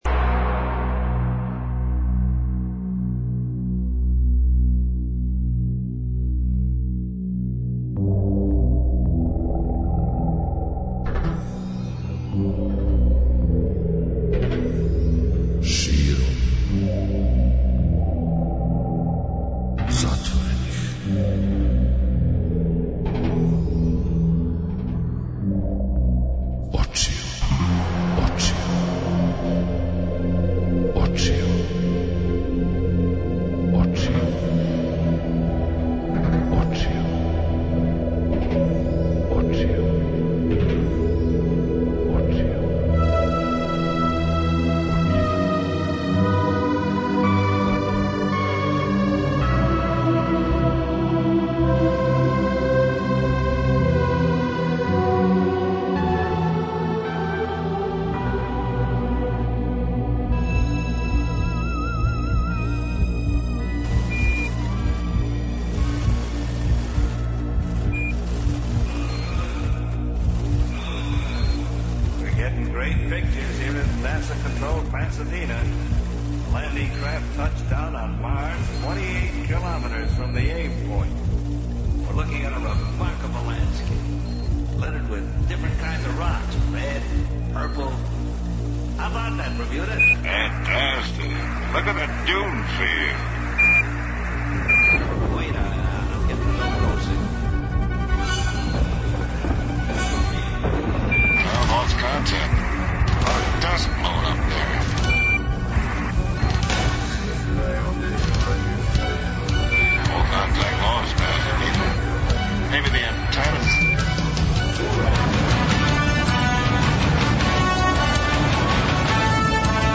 О њему су, на једној трибини, говорили
Уз бројне рубрике у сада проширеном Погледу из свемирског брода, после три сата, очекује вас и музички судар Бетмен против Супермена, у част филма који је на великом платну спојио ова два суперхероја Водитељ